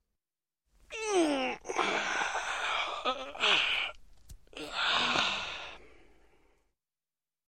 Звуки потягивания
Звук потягивания после сна